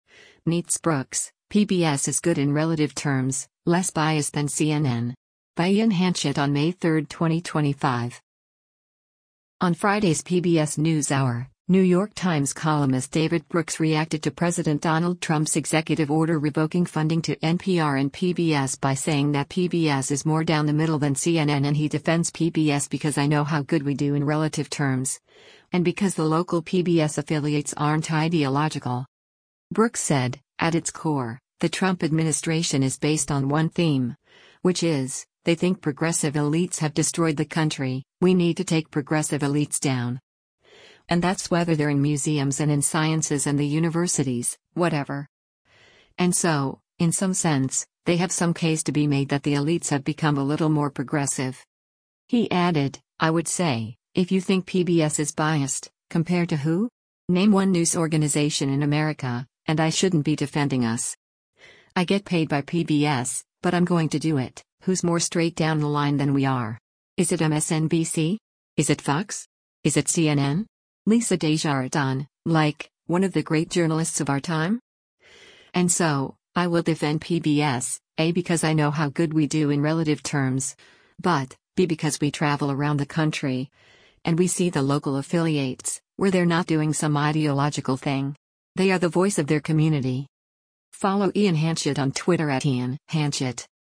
On Friday’s “PBS NewsHour,” New York Times columnist David Brooks reacted to President Donald Trump’s executive order revoking funding to NPR and PBS by saying that PBS is more down the middle than CNN and he defends PBS “because I know how good we do in relative terms,” and because the local PBS affiliates aren’t ideological.